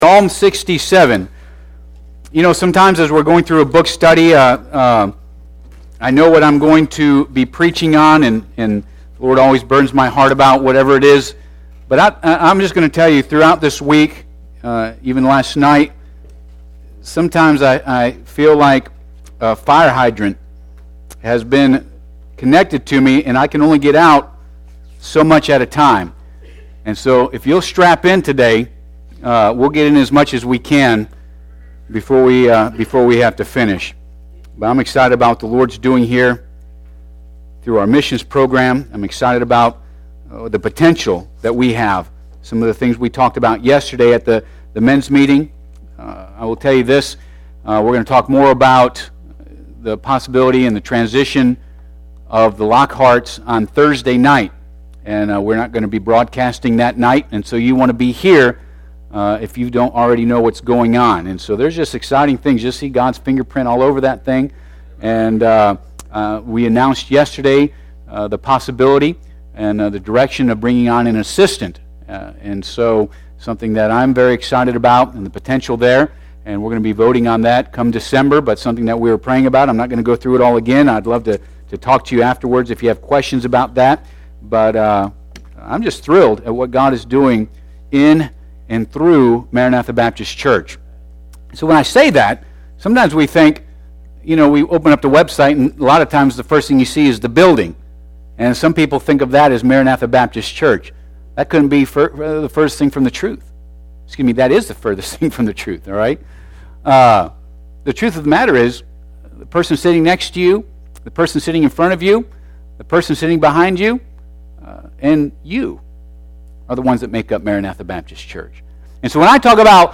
Passage: Psalm 67 Service Type: Sunday AM